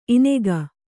♪ inega